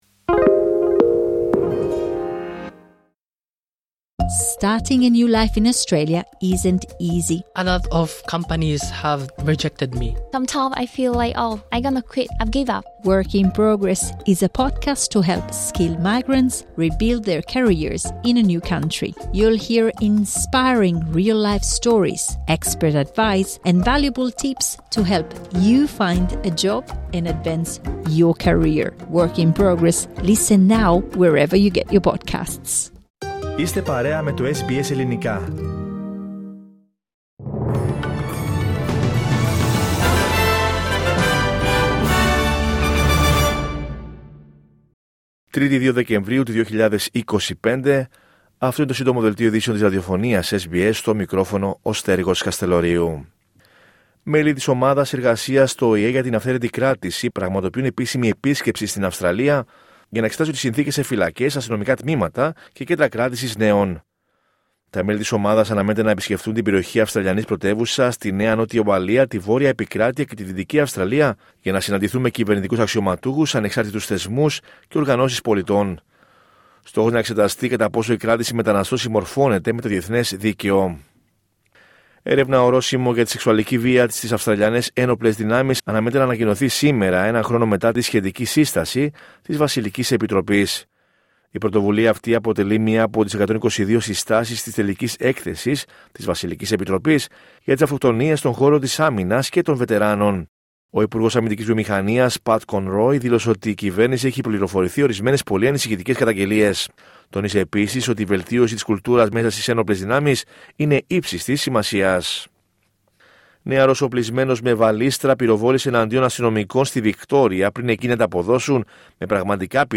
H επικαιρότητα έως αυτή την ώρα στην Αυστραλία, την Ελλάδα, την Κύπρο και τον κόσμο στο Σύντομο Δελτίο Ειδήσεων της Τρίτης 2 Δεκεμβρίου 2025.